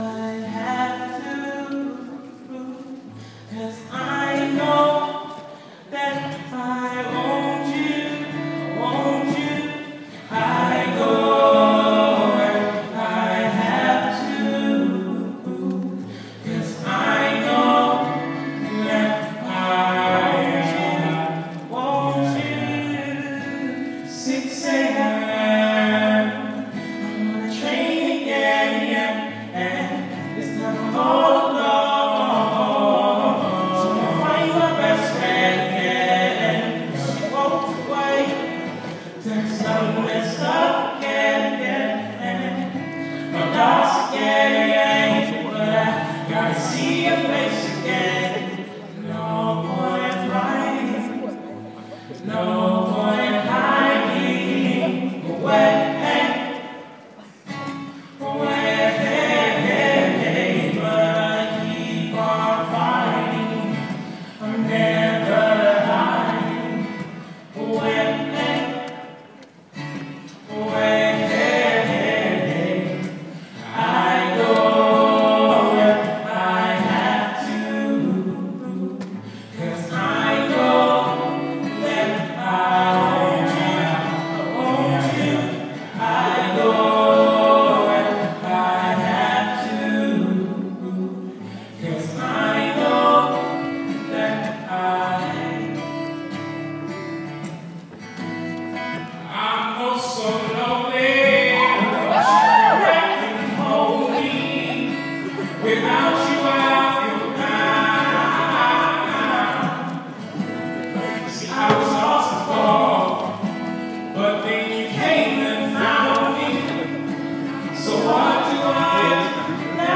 R'n'B duo
at Town Hall Birmingham